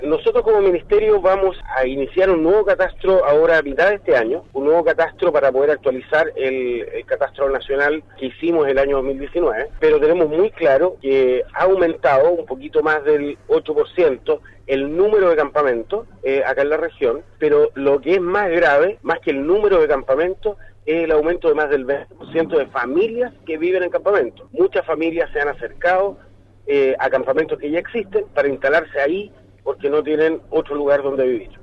En la Región de Los Lagos, esta intervención acelerará la gestión habitual pasando de un promedio de tres campamentos anuales a seis campamentos este año, con un alcance de 283 familias beneficiadas, así lo comentó a Radio Sago, el Seremi de Vivienda y Urbanismo, Jorge Guevara.